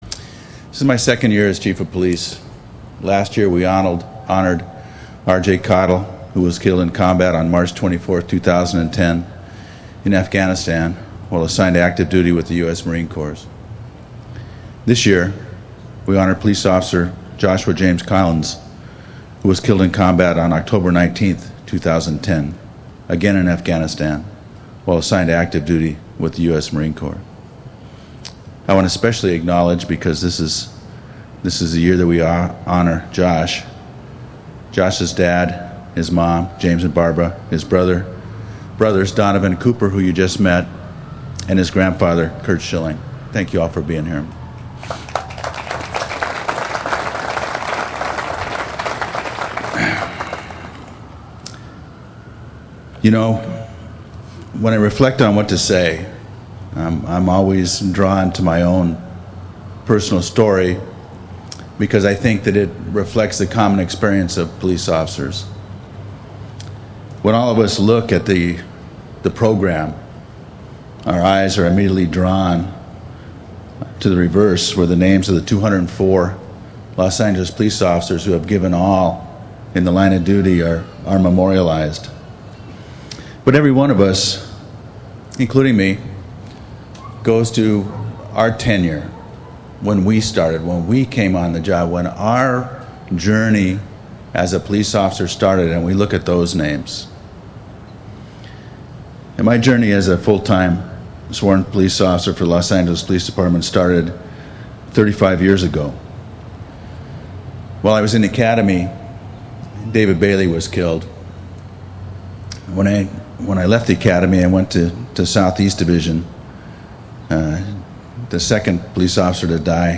Please click on the link to listen to LAPD Chief Charlie Beck speaking at the ceremony to the family members gathered to remember their fallen loved ones.
memorial-ceremony.mp3